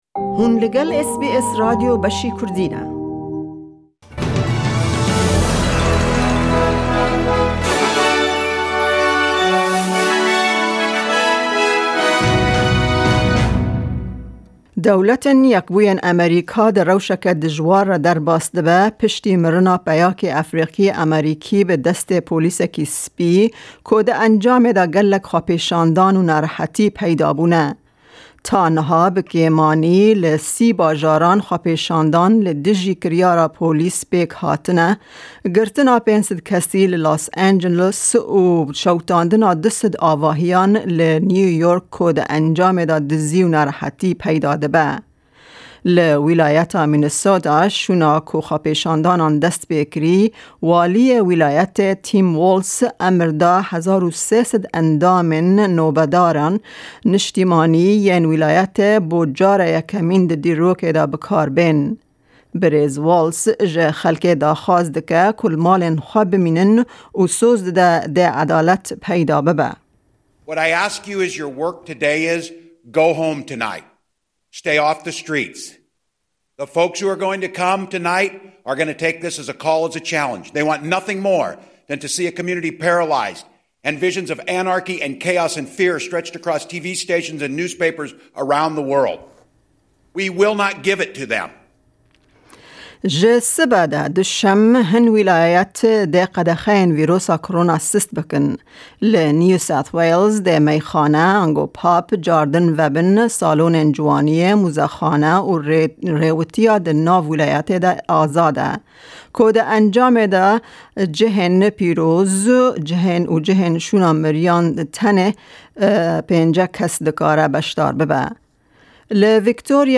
Nûçeyên roja Yekşemê